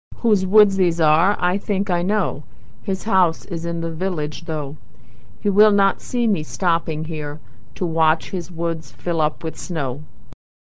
Reading - Robert Frost - Authentic American Pronunciation